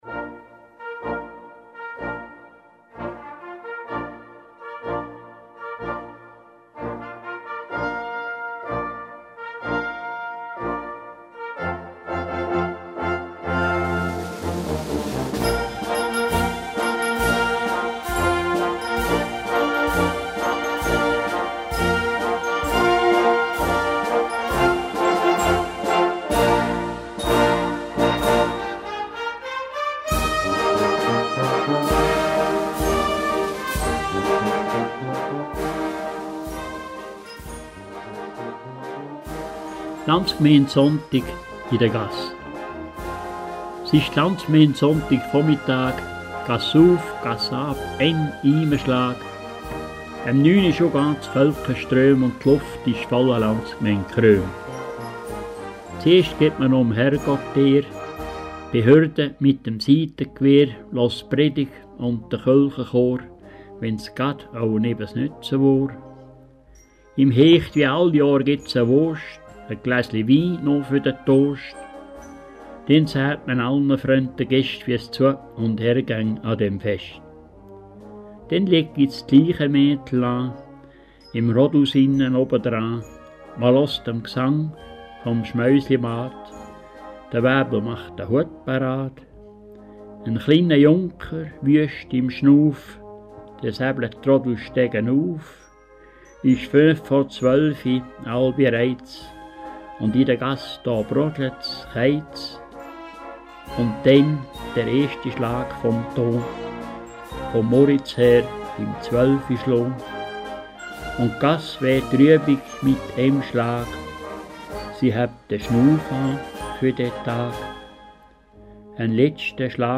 Und so tönt die Landsgemeinde: Die "Marcia solenne" / Landsgemeinde-Marsch.